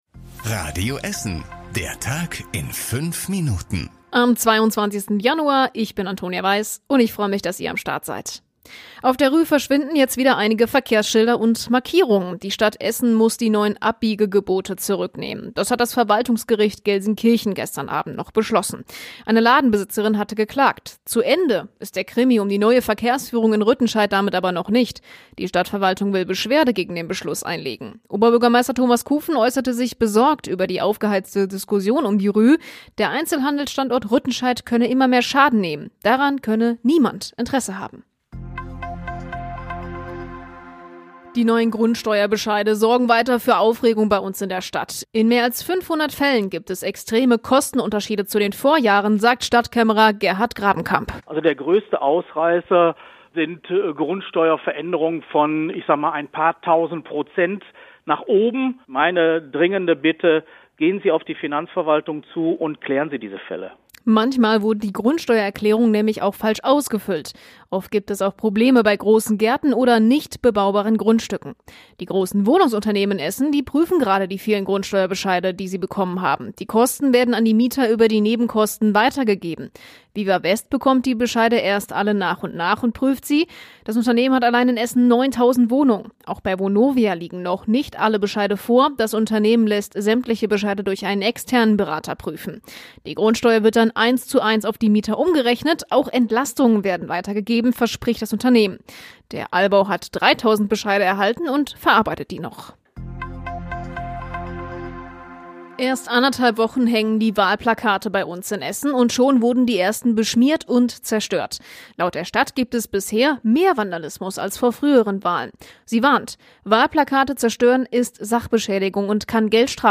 Unsere Nachrichtenredakteure fassen den Tag für Euch noch mal zusammen.
Täglich um 19.30 bei uns im Radio.